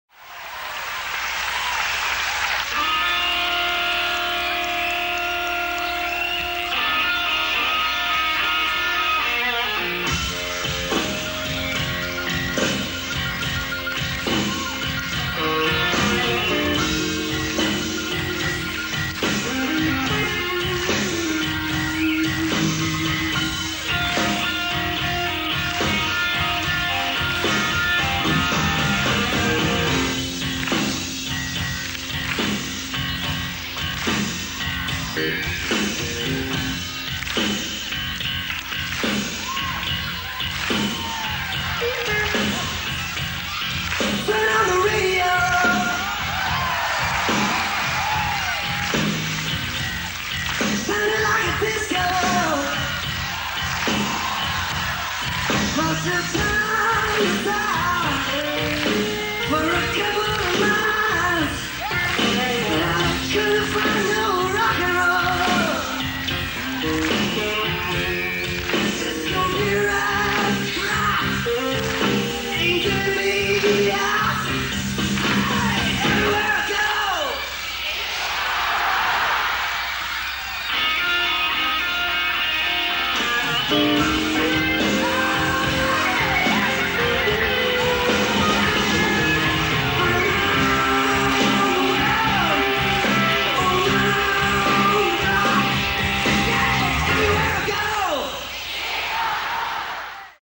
Genre: Rock/Pop
Blues Version